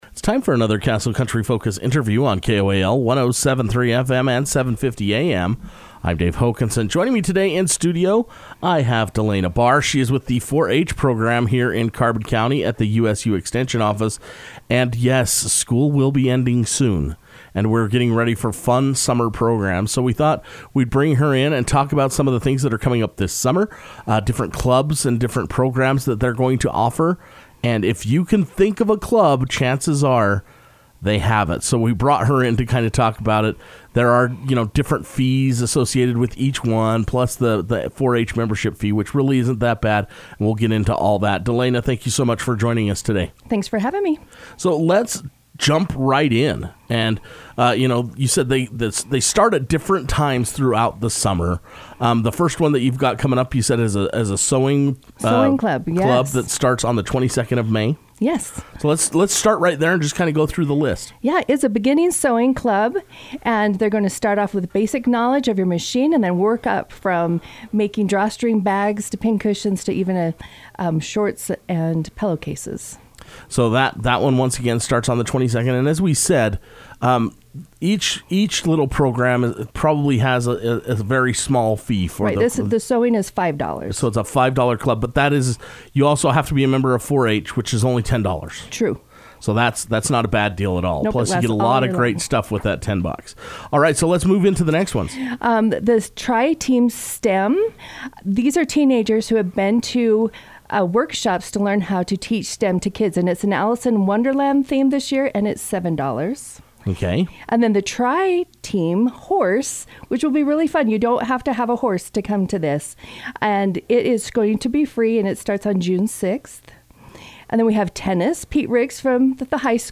stopped by Castle Country Radio to share all the wonderful details of the summer programs.